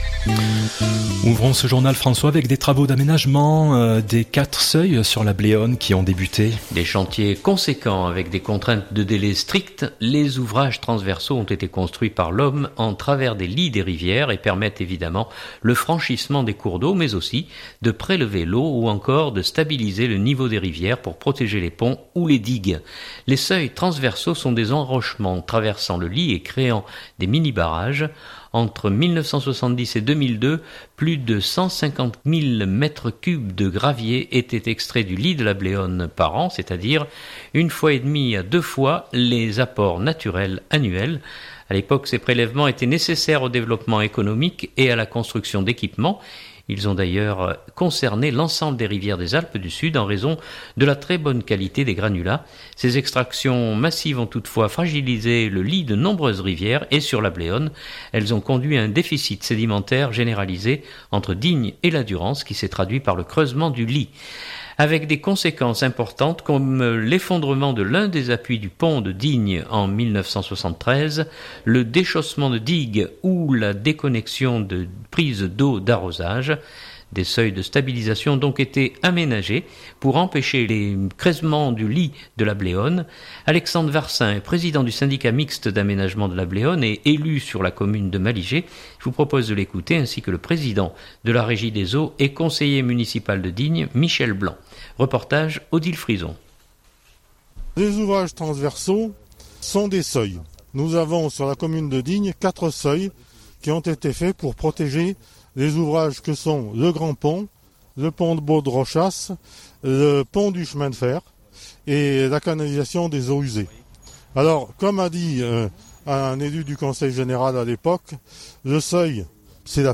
Des seuils de stabilisation ont donc été aménagés pour empêcher le creusement du lit de la Bléone. Alexandre Varcin est Président du syndicat mixte d’aménagement de la Bléone et élu sur la commune de Malijai. Je vous propose de l’écouter, ainsi que le Président de la régie des eaux et conseiller municipal de Digne Michel Blanc.